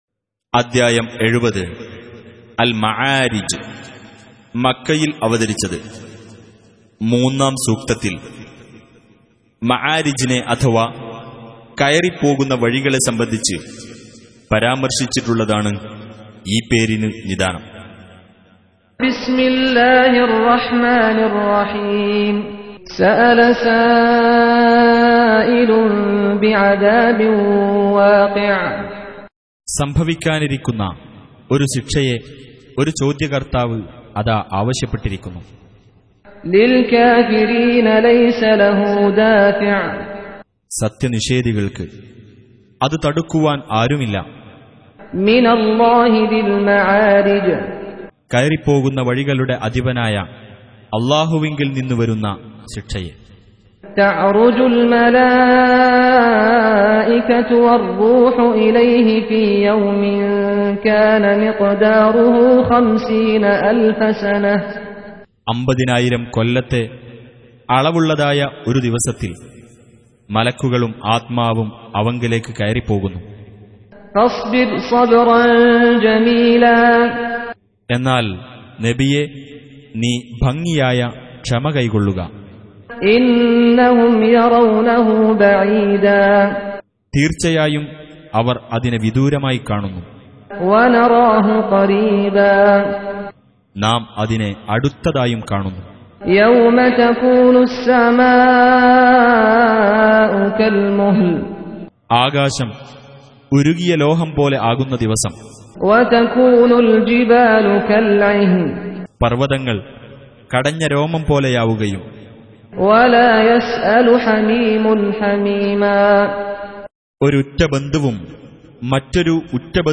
Malayalam Translation Of The Holy Quran Recitation
Surah Repeating تكرار السورة Download Surah حمّل السورة Reciting Mutarjamah Translation Audio for 70. Surah Al-Ma'�rij سورة المعارج N.B *Surah Includes Al-Basmalah Reciters Sequents تتابع التلاوات Reciters Repeats تكرار التلاوات